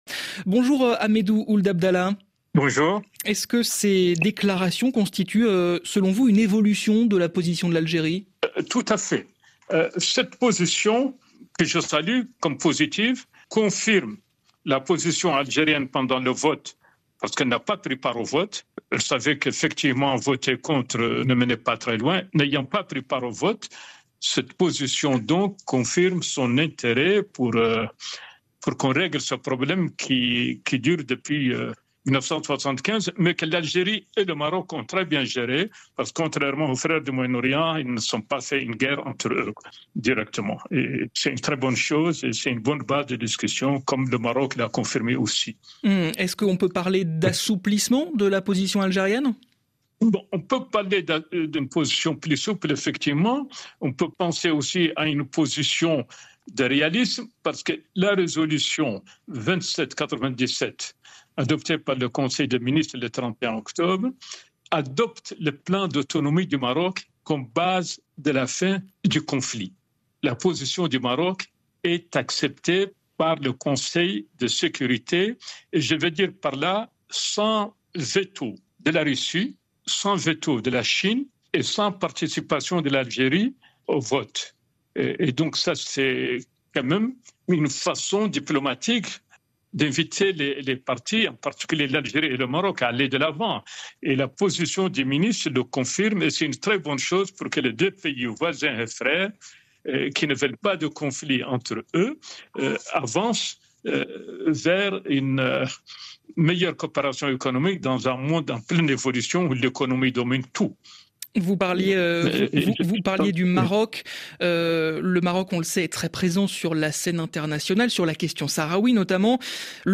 Ahmedou Ould-Abdallah, ancien ministre mauritanien des Affaires étrangères et président du Centre 4S (Stratégie, Sécurité, Sahel, Sahara) est l’invité d’Afrique midi.